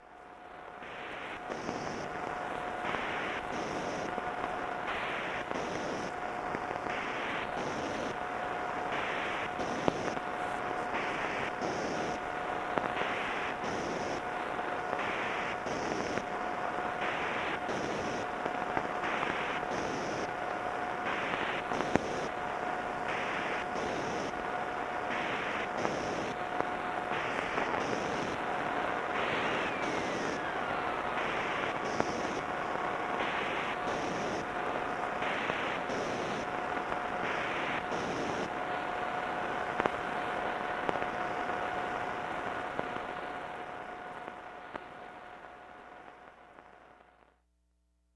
operating in the low frequency band, and intended for military communications during a nuclear war.
I made this recording at around 150 kHz sometime in the mid 1990s in New Mexico.